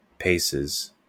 Ääntäminen
Ääntäminen US US Tuntematon aksentti: IPA : /ˈpeɪsɪz/ Haettu sana löytyi näillä lähdekielillä: englanti Käännöksiä ei löytynyt valitulle kohdekielelle.